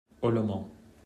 Ollomont (French: [ɔlɔmɔ̃]
Fr-Ollomont.mp3